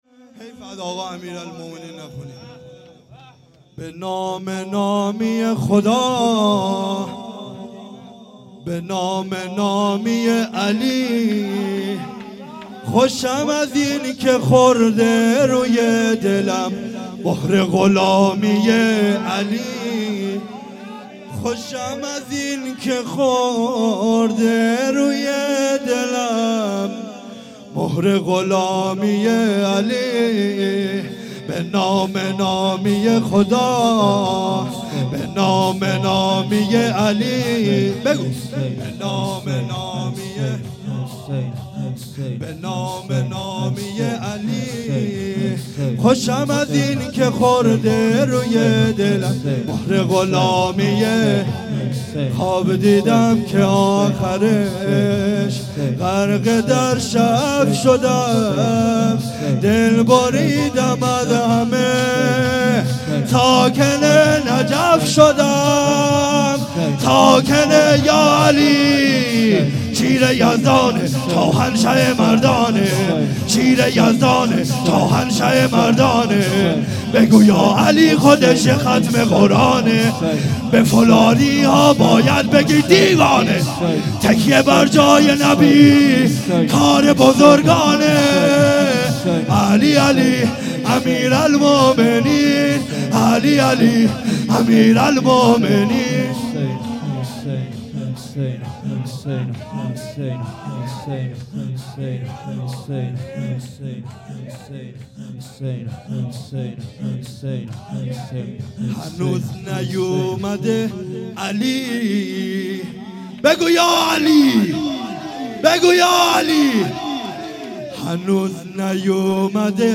مراسم هفتگی